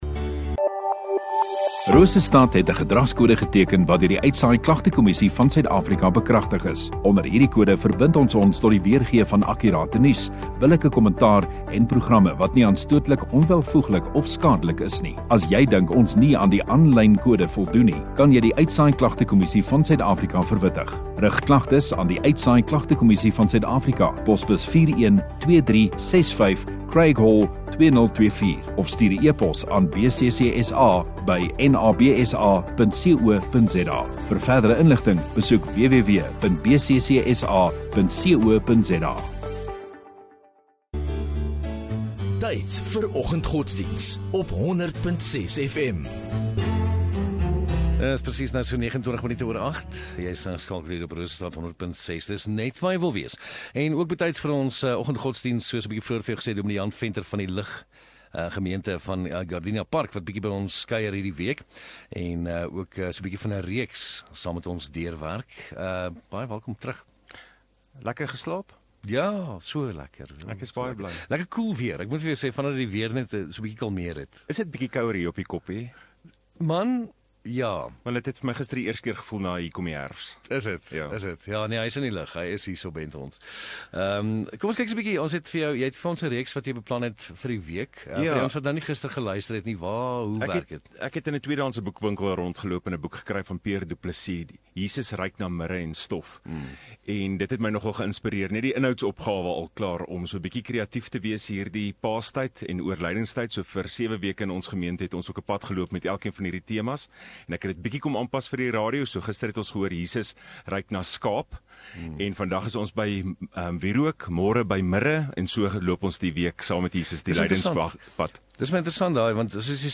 26 Mar Dinsdag Oggenddiens